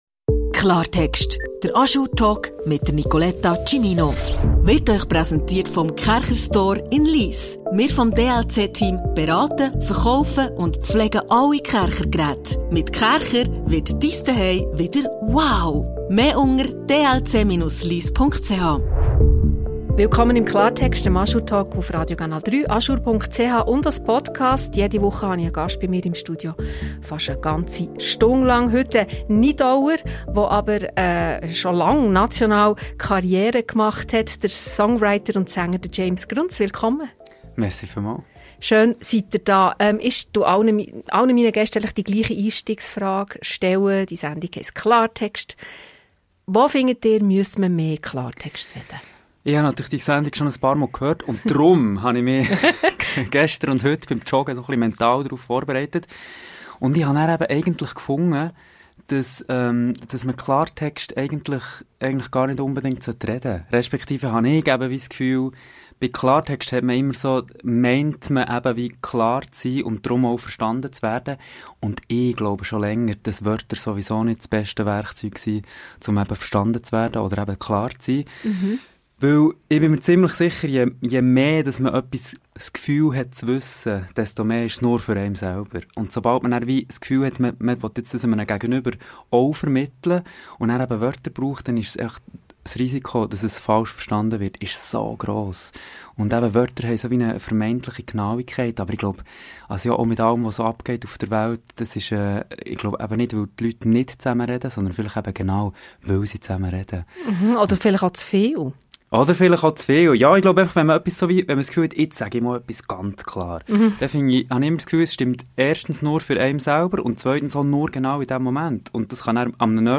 Ein Gespräch über die Kunst des Loslassens, das Vatersein und die Suche nach Echtheit in einer lauten Welt.